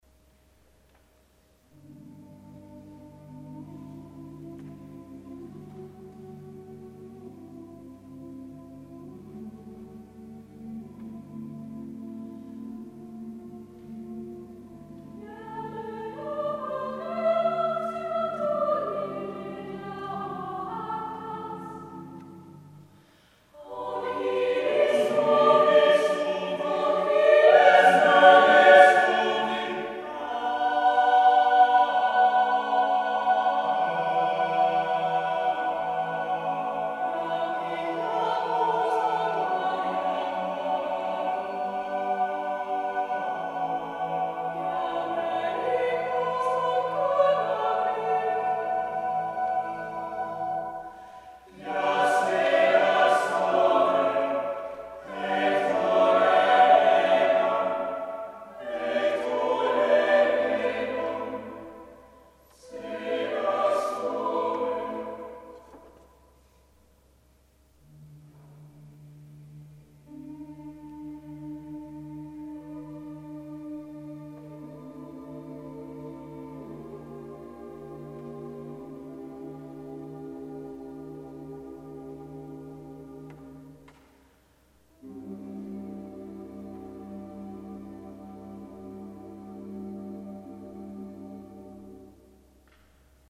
Opnames van het Erasmus Kamerkoor
Opnames concerten februari 2007 (project Noorderlicht)